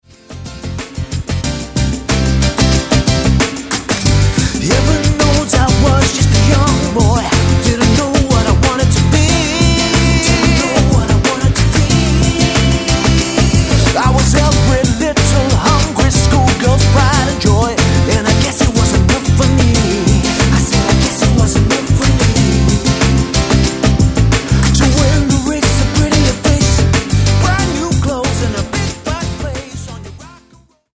--> MP3 Demo abspielen...
Tonart:C Multifile (kein Sofortdownload.